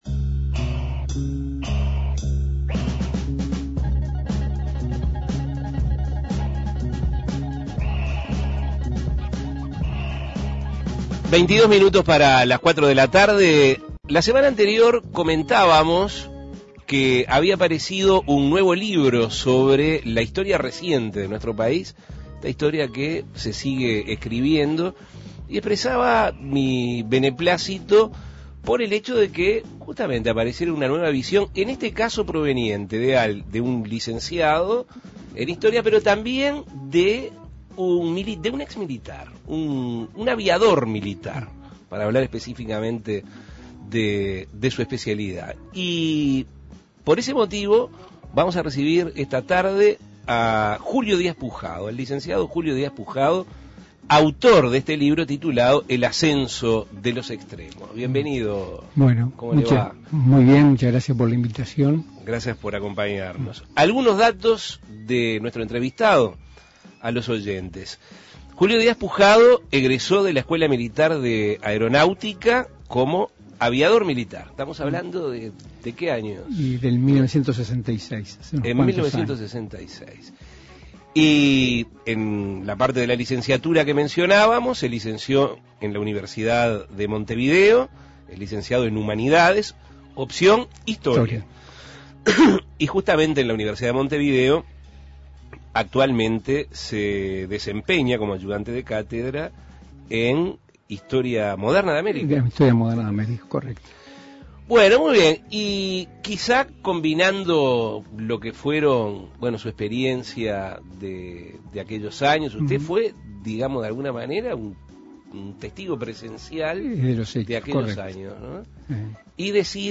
Los distintos factores políticos e institucionales que operaron, son puestos en escena para vislumbrar el peso que tuvieron en el desenlace de 1973. Escuche la entrevista.